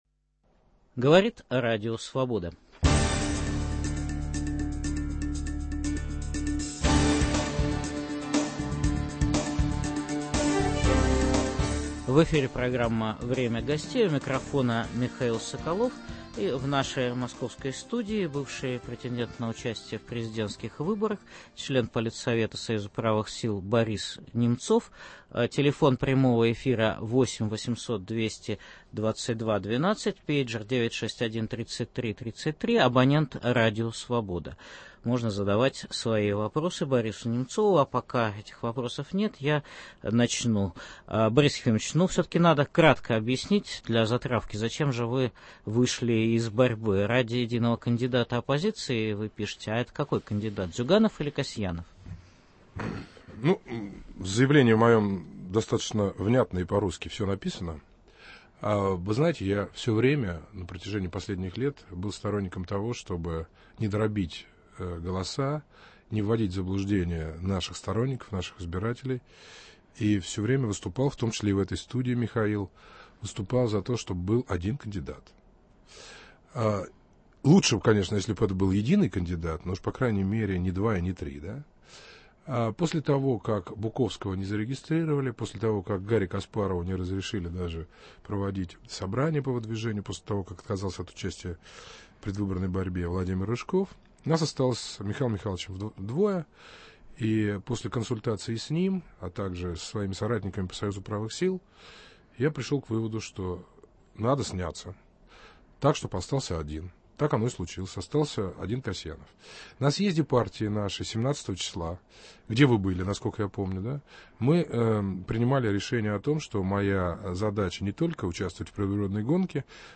В программе выступит несостоявшийся кандидат в президенты России Борис Немцов.